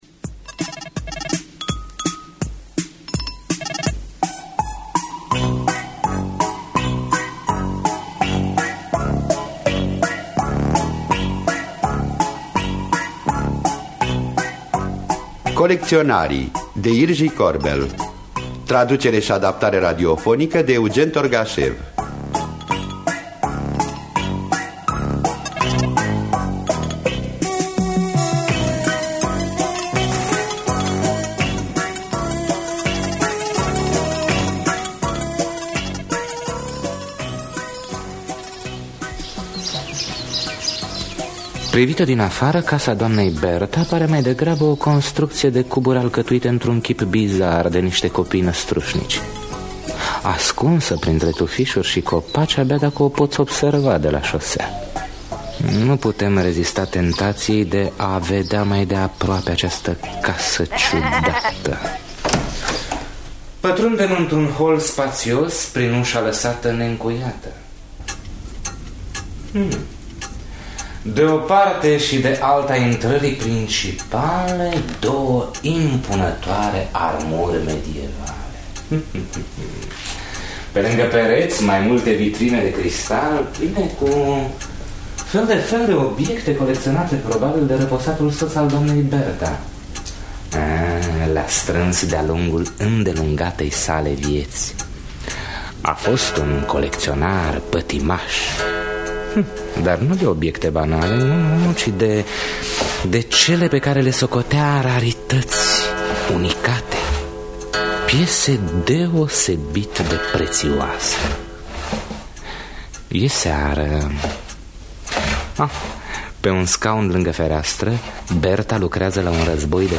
Colecționarii de Jiří Korbel – Teatru Radiofonic Online